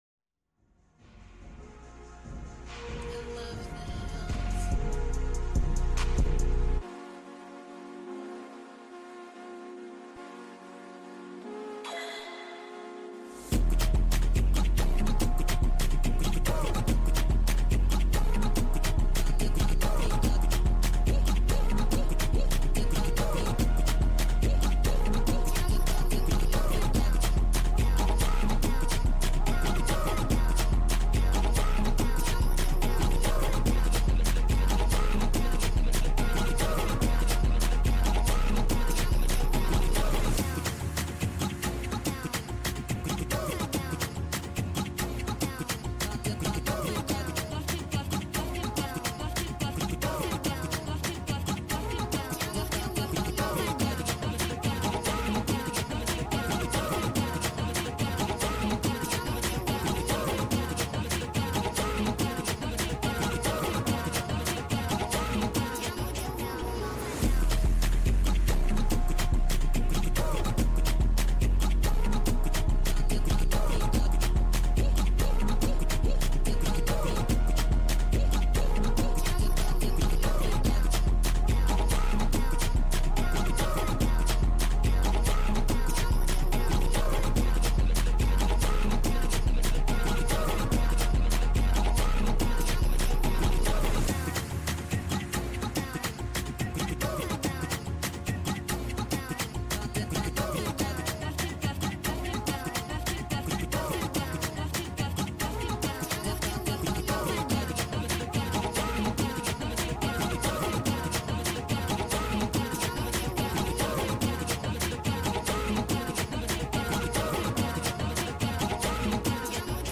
Full Sped Up version